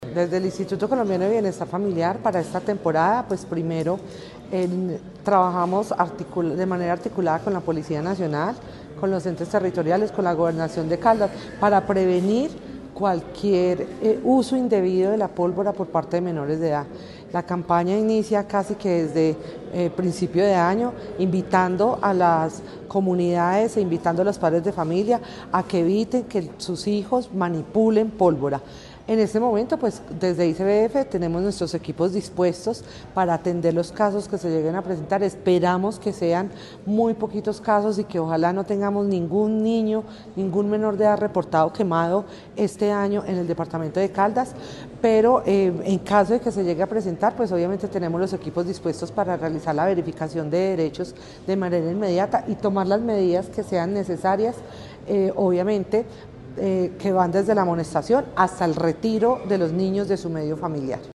La Gobernación de Caldas, en articulación con la Dirección Territorial de Salud de Caldas (DTSC), el Instituto Colombiano de Bienestar Familiar (ICBF) y la Policía Nacional, realizó el lanzamiento oficial de la campaña departamental de prevención del uso de pólvora para la temporada 2025.